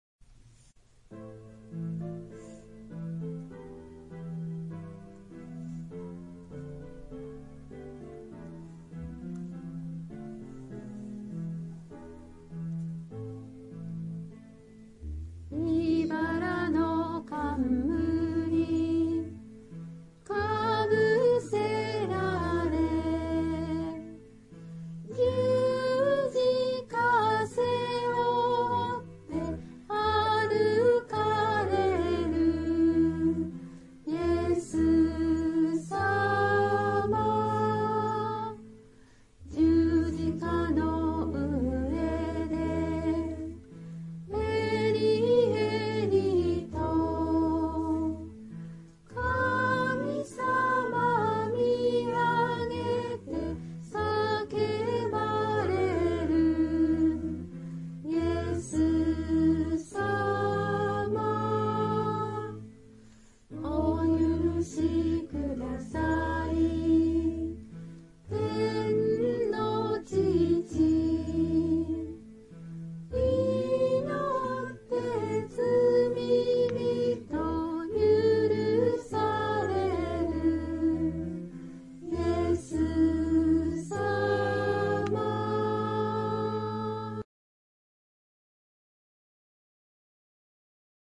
唄